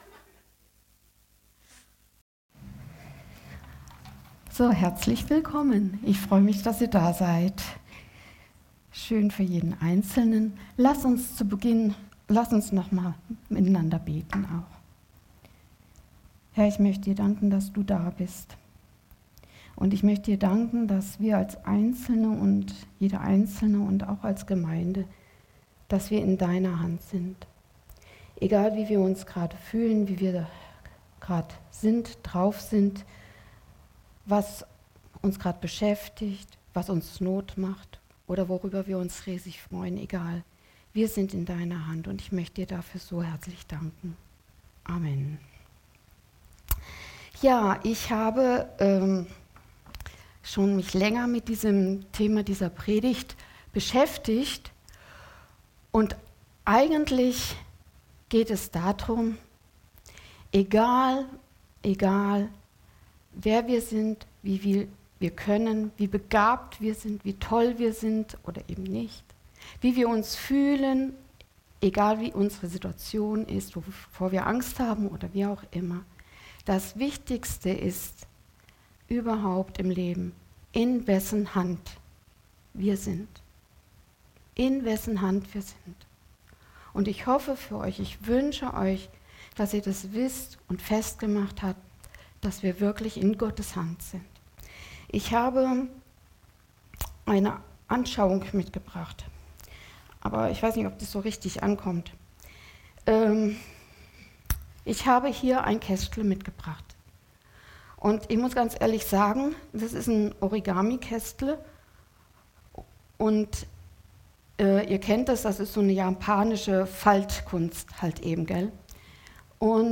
Predigt vom 23. Juni 2024 – Süddeutsche Gemeinschaft Künzelsau